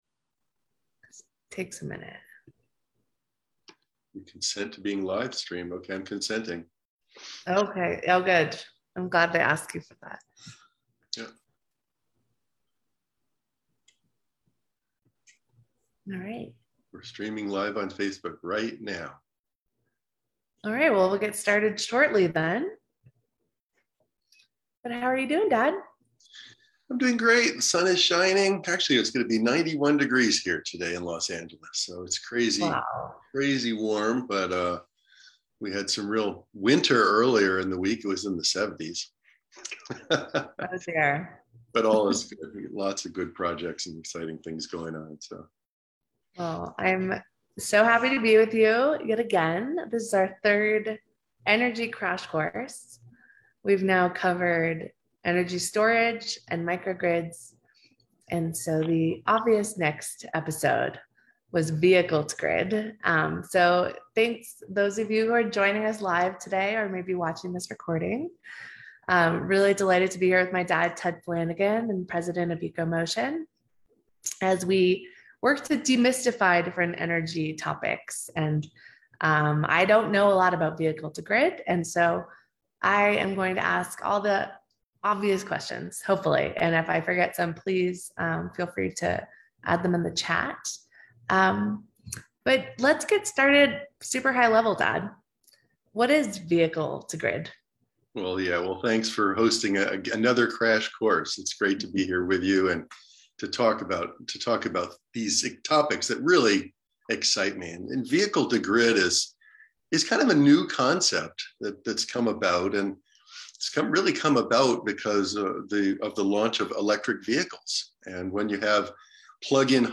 Live Discussion on Vehicle to Grid